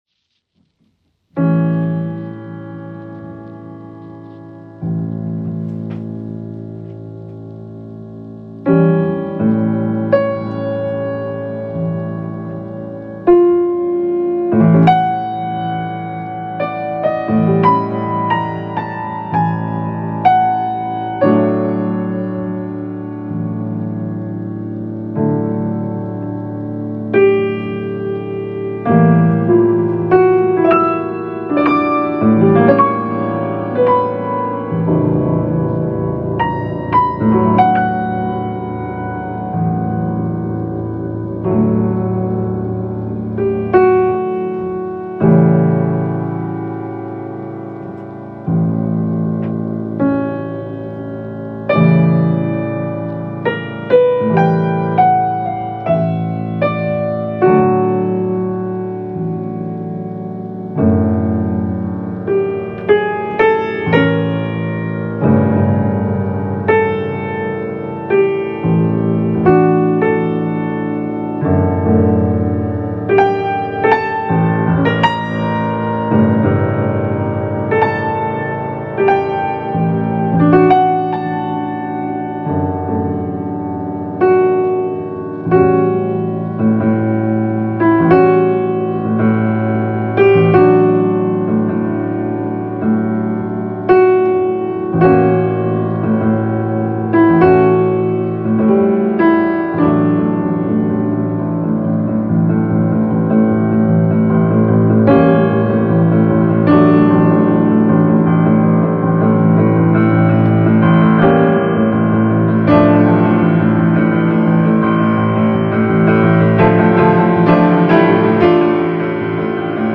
- Piano Music, Solo Keyboard - Young Composers Music Forum